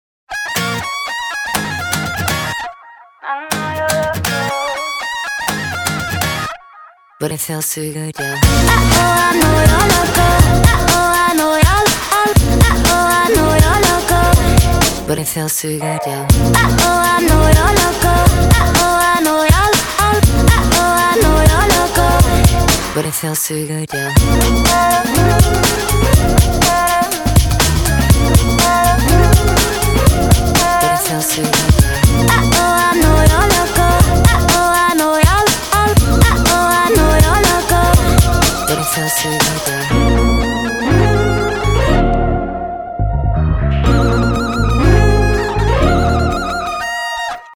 • Качество: 320, Stereo
поп
dance
Забавная музыка